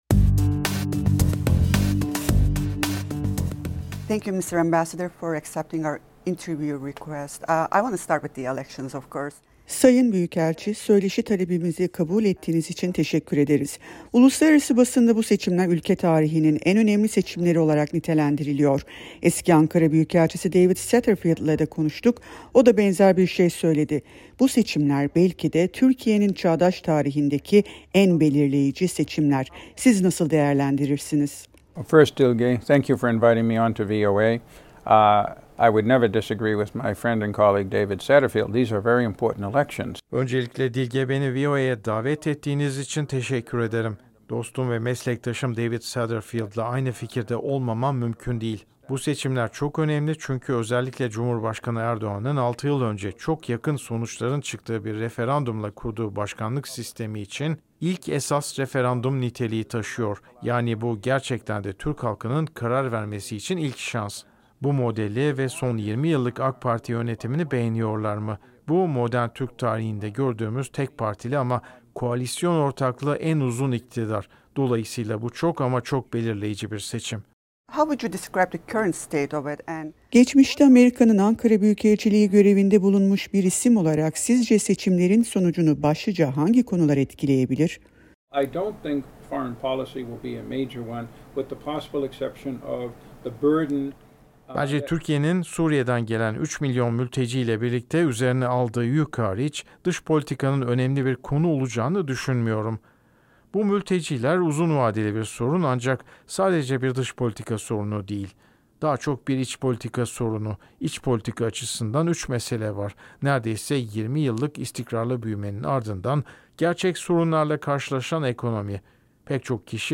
Seçim sürecinde Seçim 2023 Videocast serimizde VOA Türkçe’nin sorularını yanıtlayan Eski ABD Büyükelçisi James Jeffrey’e göre, 16 Nisan 2017 Referandumu'yla kabul edilen cumhurbaşkanlığı hükümet sistemi için 14 Mayıs, halkın esas karar günü olacak